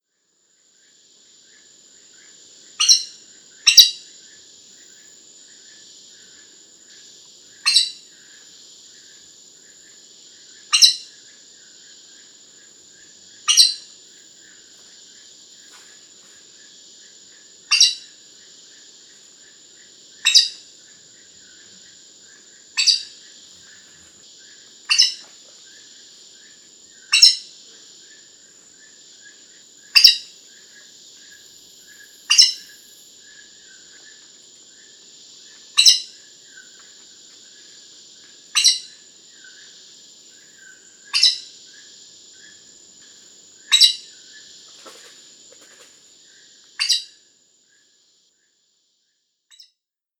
Vocalización de tucancillo collarejo, Pteroglossus torquatus.
pteroglossus_torquatus_sebr.mp3